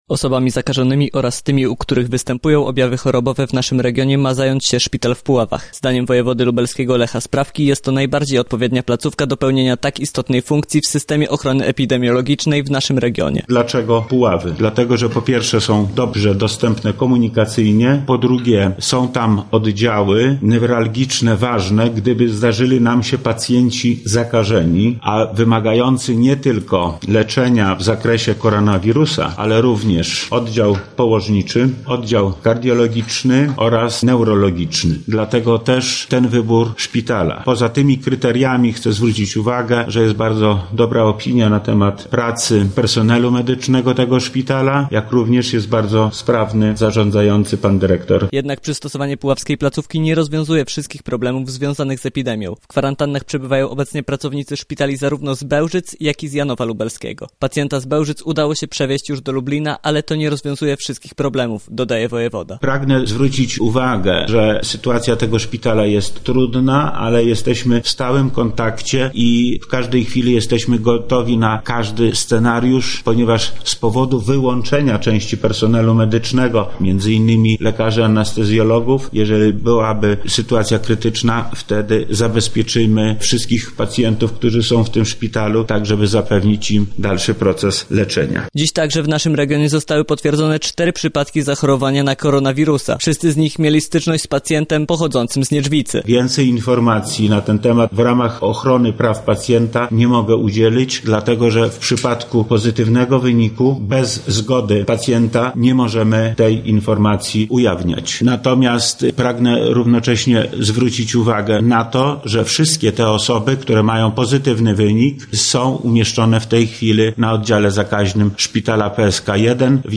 Więcej w tej sprawie, a także na temat bieżącej informacji odnoście COVID-19 w województwie, wie nasz reporter: